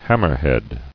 [ham·mer·head]